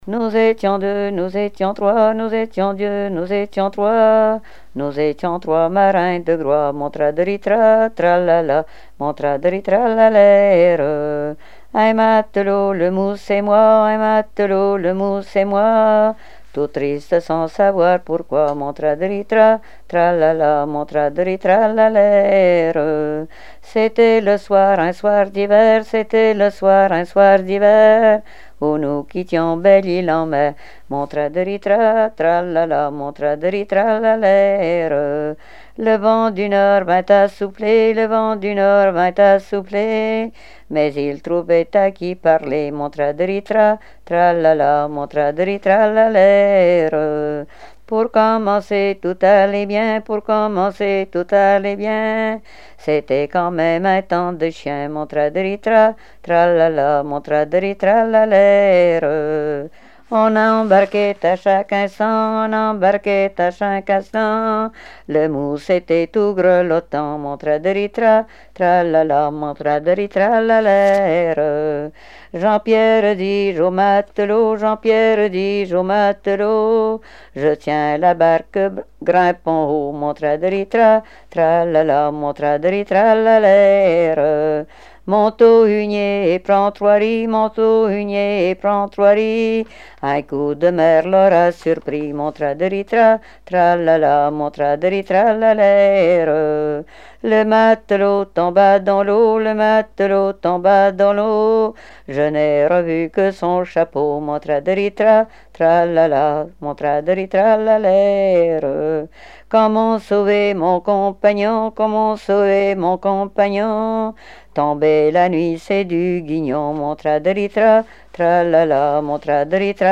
Genre laisse
Répertoire de chansons traditionnelles et populaires
Pièce musicale inédite